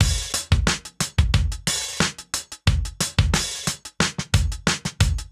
Index of /musicradar/sampled-funk-soul-samples/90bpm/Beats
SSF_DrumsProc2_90-03.wav